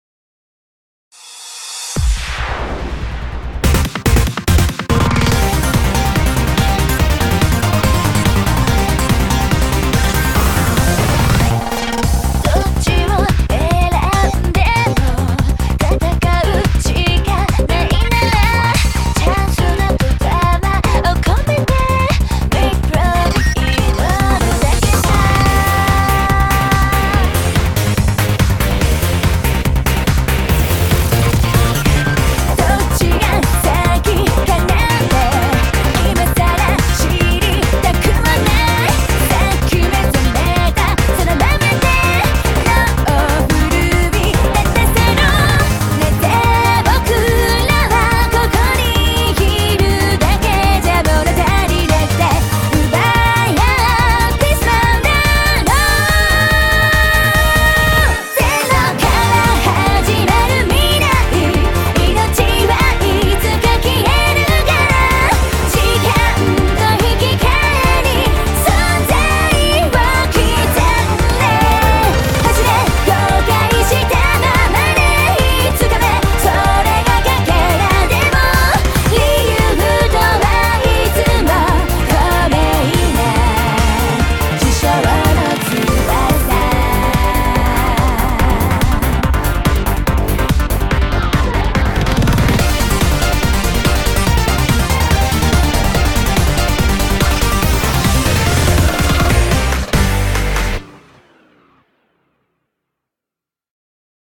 BPM143
Audio QualityCut From Video